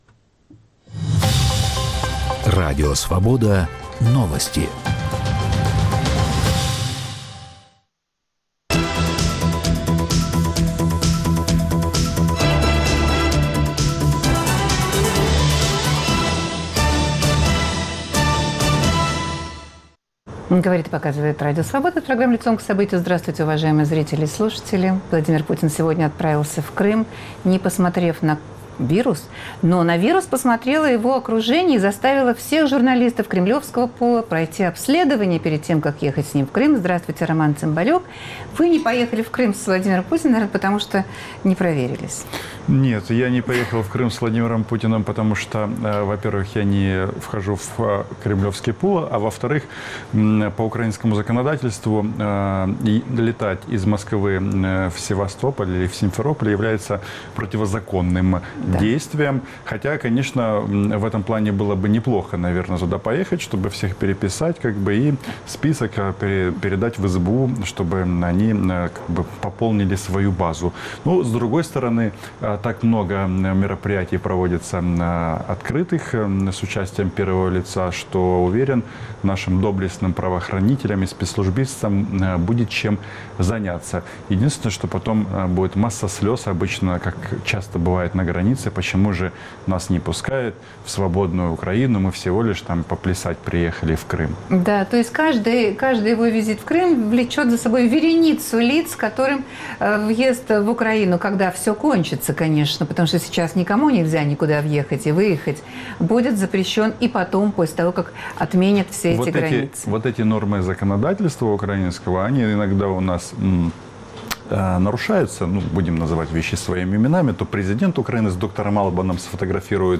историк и политический аналитик